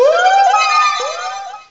pokeemerald / sound / direct_sound_samples / cries / lumineon.aif